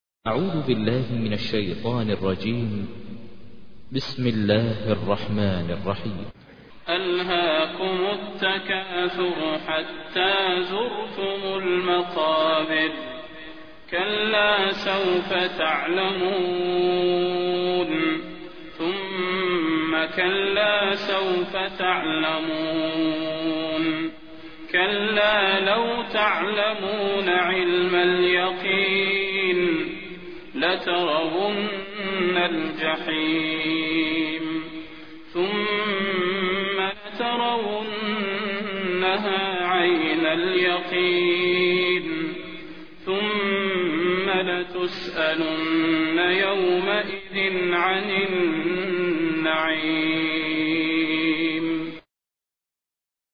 تحميل : 102. سورة التكاثر / القارئ ماهر المعيقلي / القرآن الكريم / موقع يا حسين